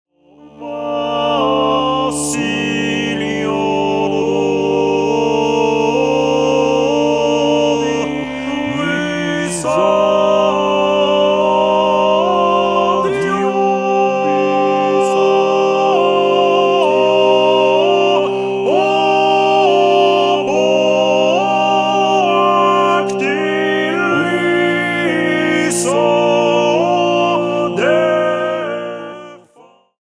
für Männerchor mit Jodel Solo (ad lib.)
Beschreibung:Chormusik; Chor weltlich
Besetzung:Männerchor (T, T, B, B)
Bemerkung:Jodel Solo ad libitum